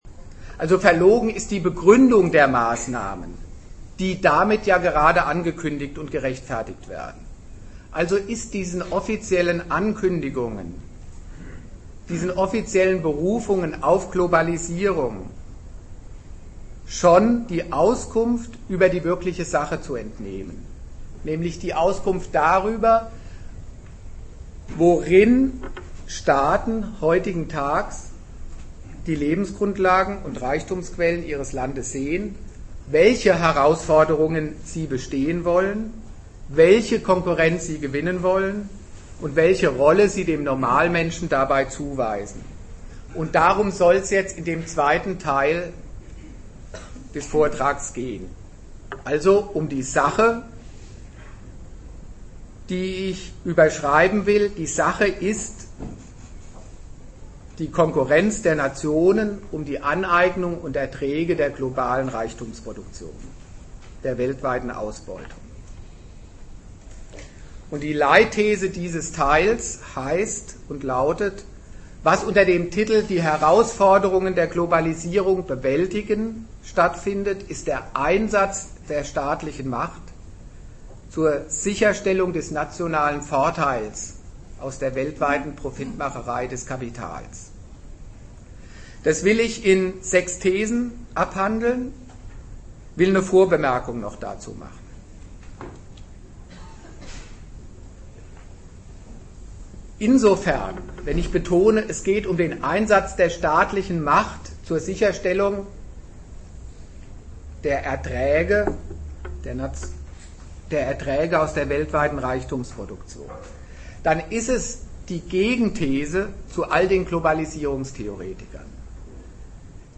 Ort Bremen
Dozent Gastreferenten der Zeitschrift GegenStandpunkt